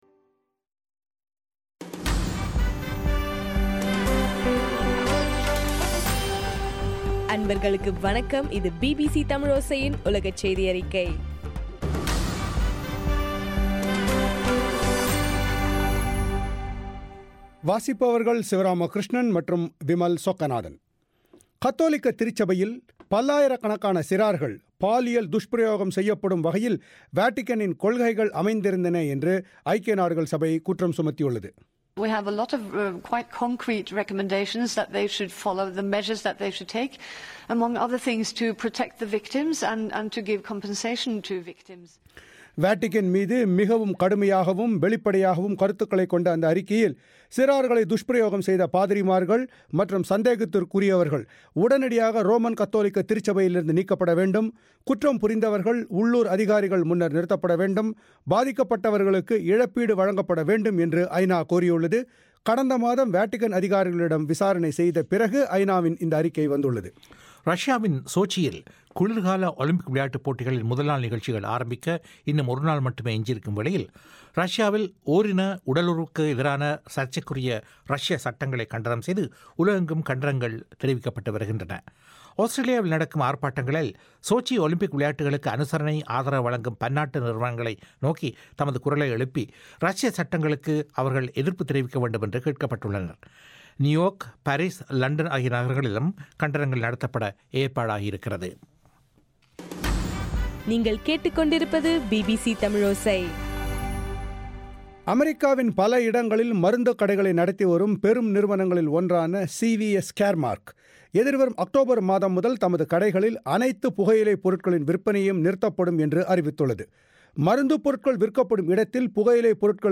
பிப்ரவரி 5, 2014 பிபிசி தமிழோசையின் உலகச் செய்திகள்